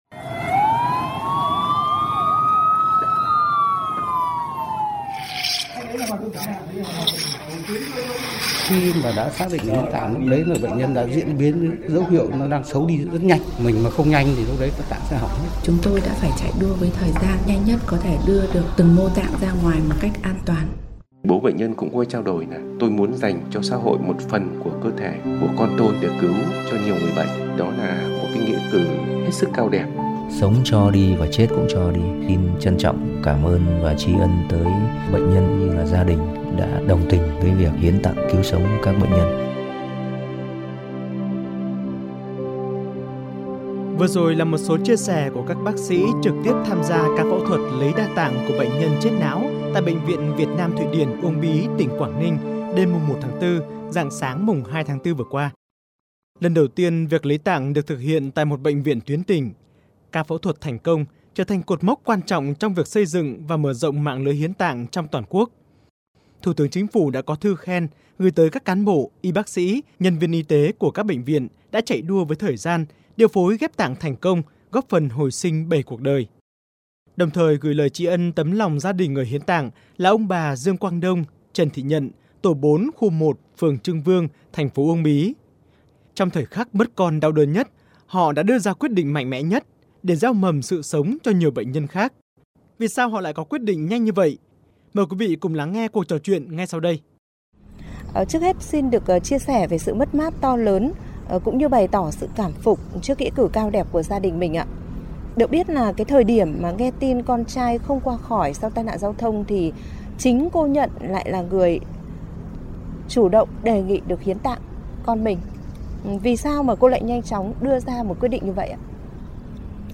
19886_TTTTQUANGNINH_PHONGVAN_Song la cho chet cung la cho.mp3